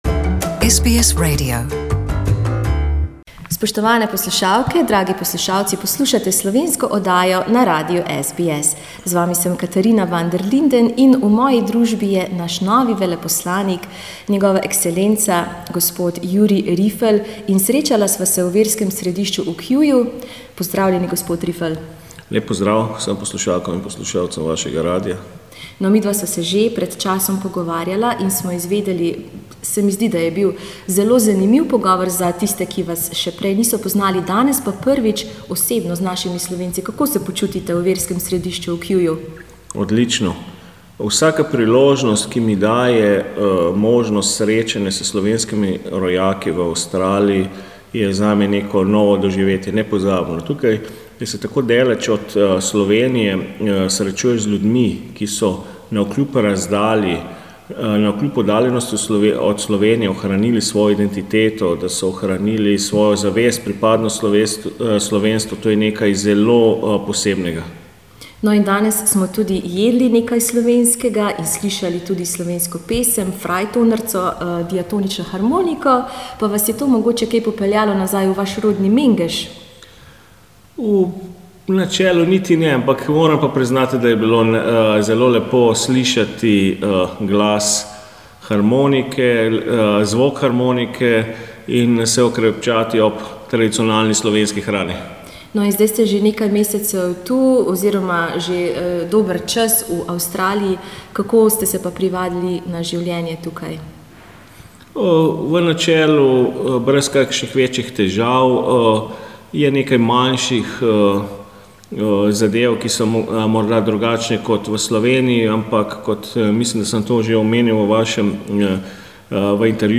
The Slovenian ambassador to Australia Jurij Rifelj speaks about his plans for the upcoming holidays.